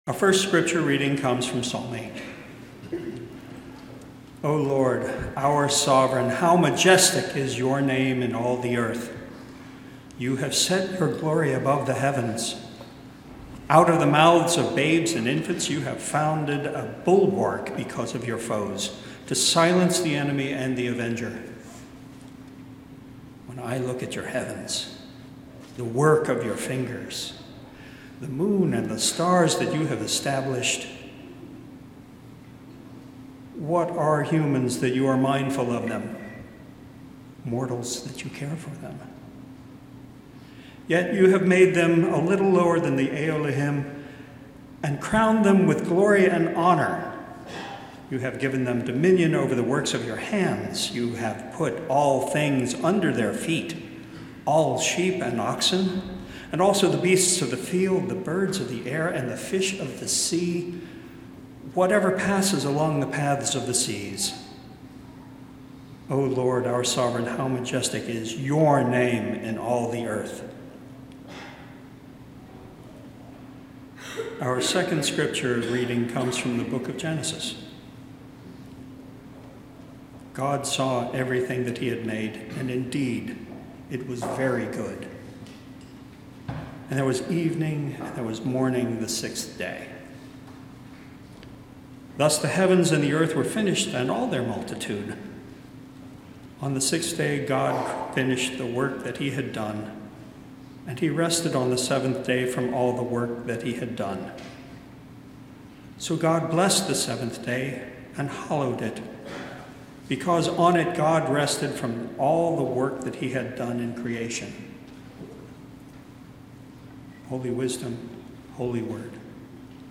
Sermon-January-4-2026-God-is-Love-Creation.mp3